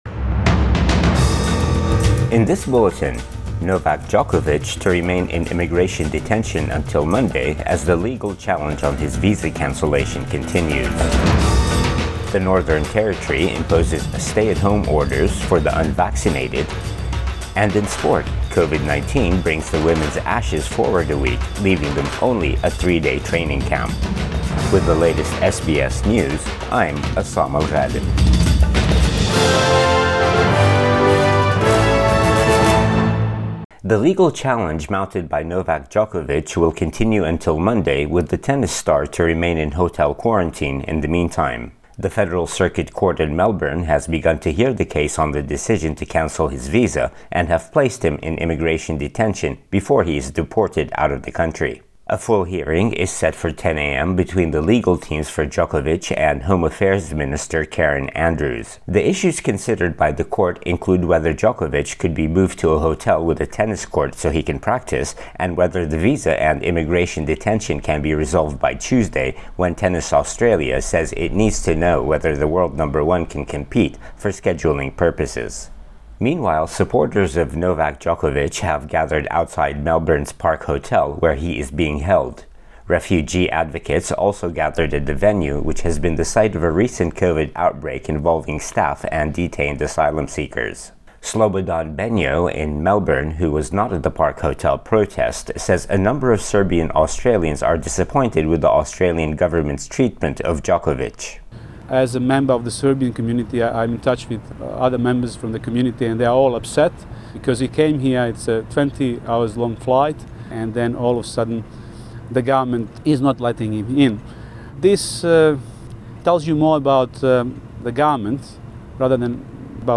AM bulletin 7 January 2022